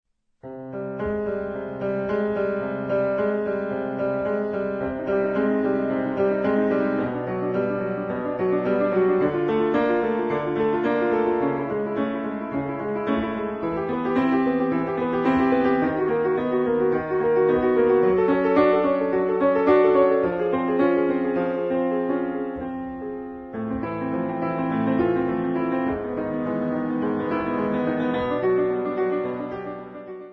12 Klavierstücke, mittelschwer
Besetzung: Klavier